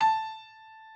piano11_5.ogg